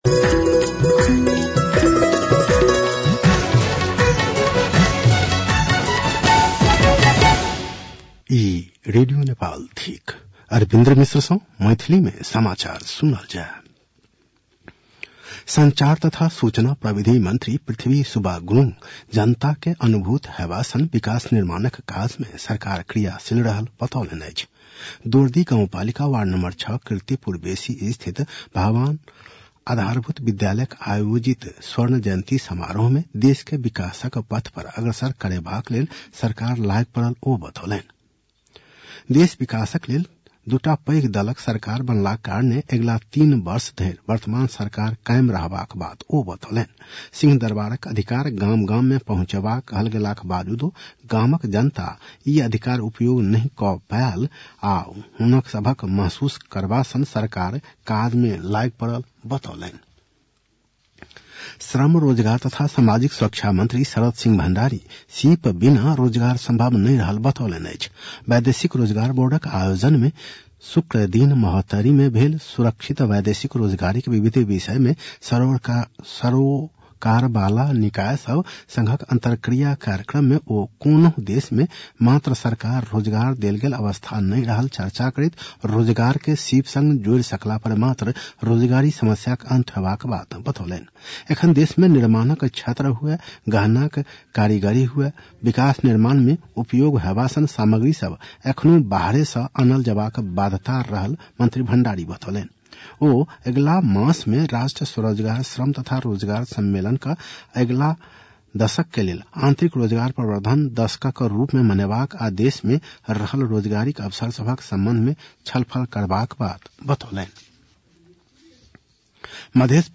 मैथिली भाषामा समाचार : १३ माघ , २०८१